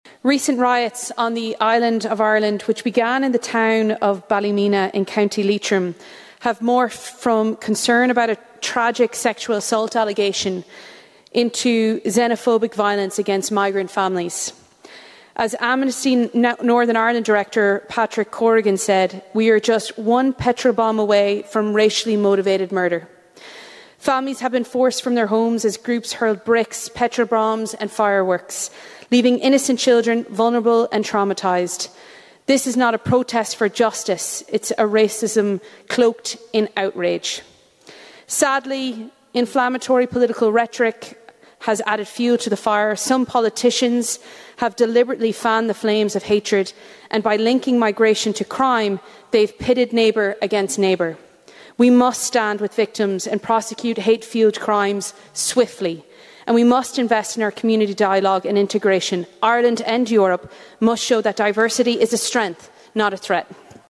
'A slip of the tongue' - MEP Maria Walsh mistakenly says ‘Ballymena, Co Leitrim’ during speech 1:03
MEP Maria Walsh has blamed a slip of the tongue after mistakenly saying Ballymena, Co Antrim was in Co Leitrim during a session of the European Parliament in Strasburg.